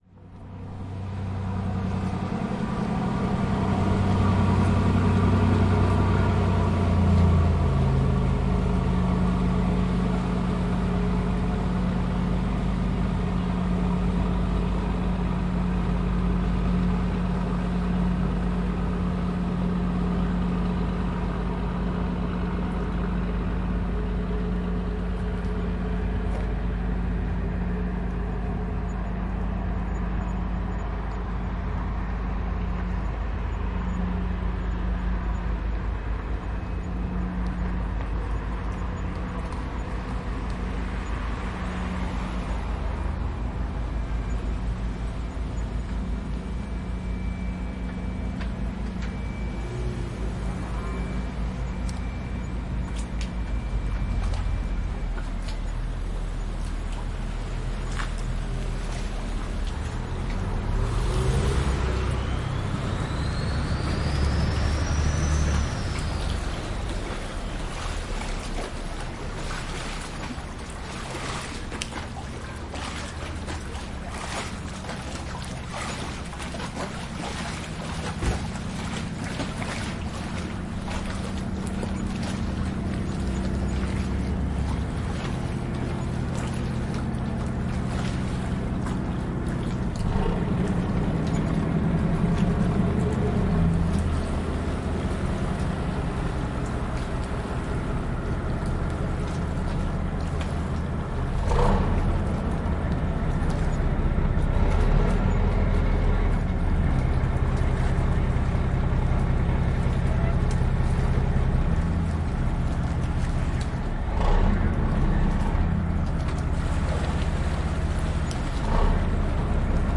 电机船发动机声
描述：电机船发动机声，效果一般
Tag: 引擎 发动机